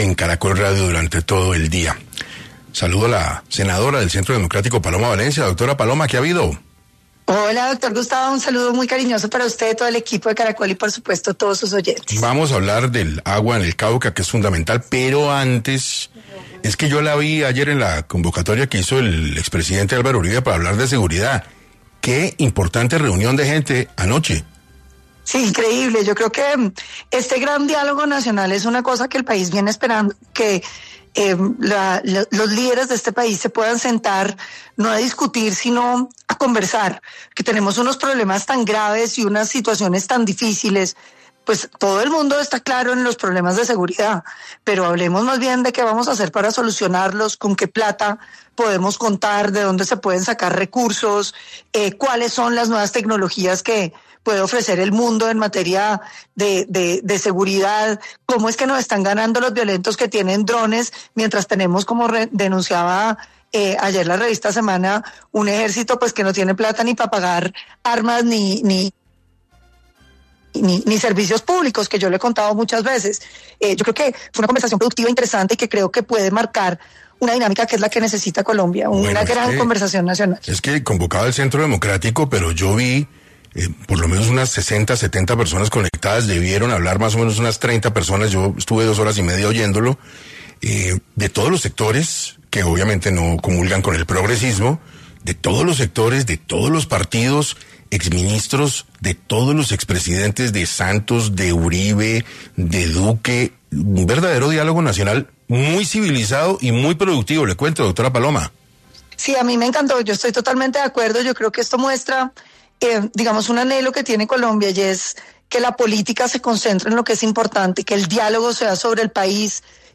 En entrevista con 6AM de Caracol Radio, Valencia expresó su preocupación por las posibles consecuencias de esta decisión argumentando que podría generar abusos y exclusión para campesinos y comunidades afros.